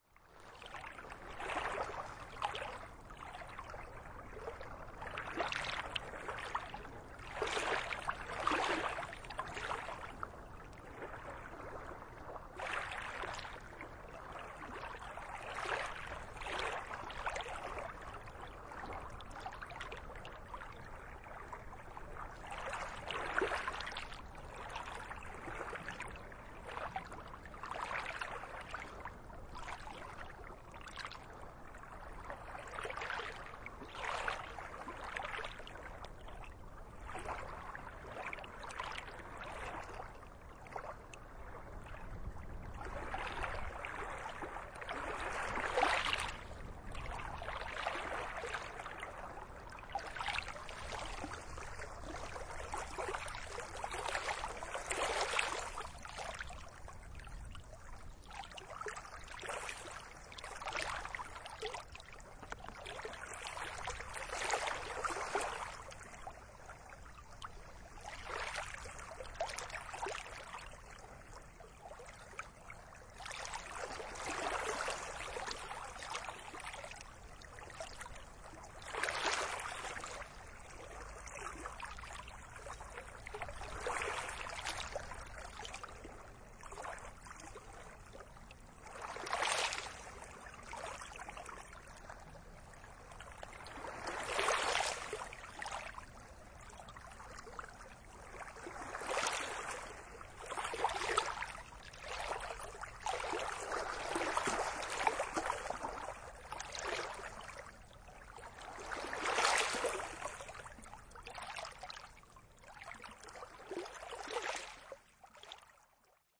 Sounds of nature
Beach.mp3